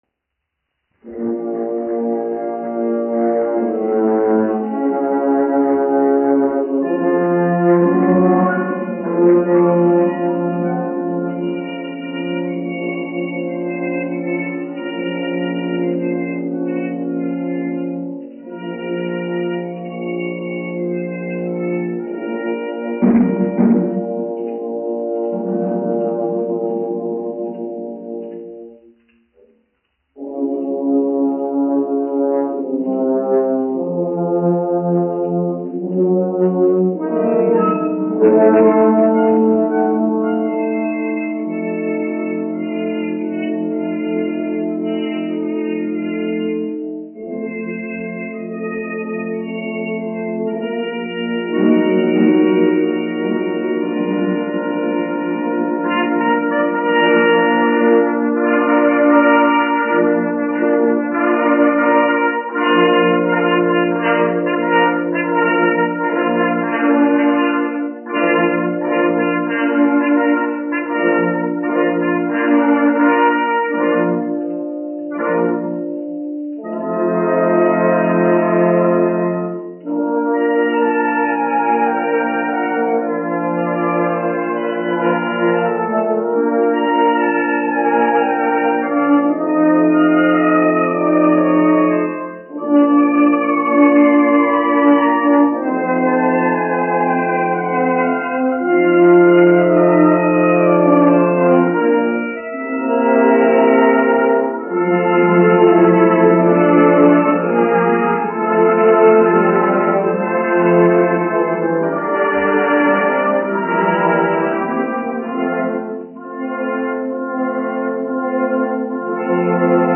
1 skpl. : analogs, 78 apgr/min, mono ; 25 cm
Pūtēju orķestra mūzika
Latvijas vēsturiskie šellaka skaņuplašu ieraksti (Kolekcija)